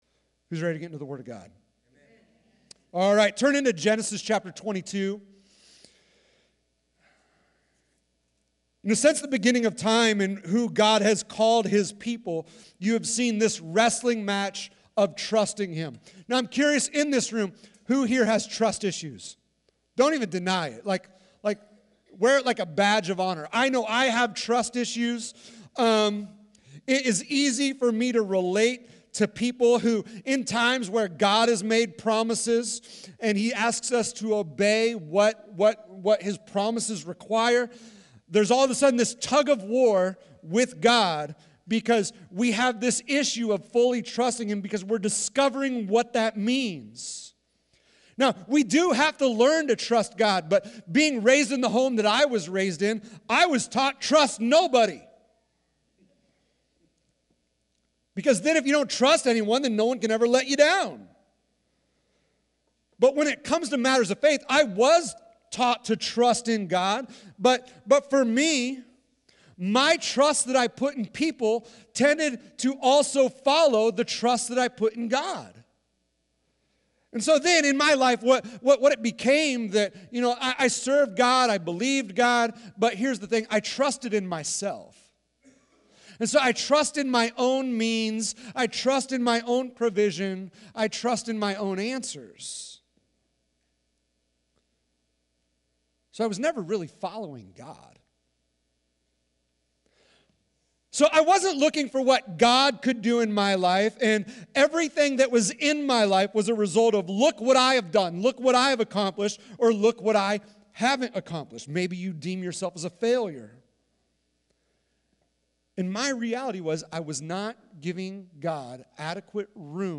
Sermons | Sunshine Open Bible Church